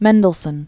(mĕndl-sən, -zōn), Felix Full name Jakob Ludwig Felix Mendelssohn-Bartholdy. 1809-1847.